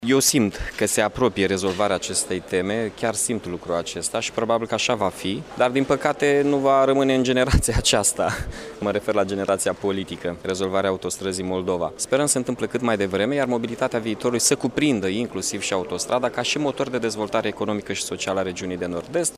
Şi primarul Iaşului, Mihai Chirica,  a vorbit despre importanţa autostrăzii în dezvoltarea oraşului şi a întregii Regiuni NORD – EST: